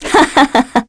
Scarlet-vox-Happy3_kr.wav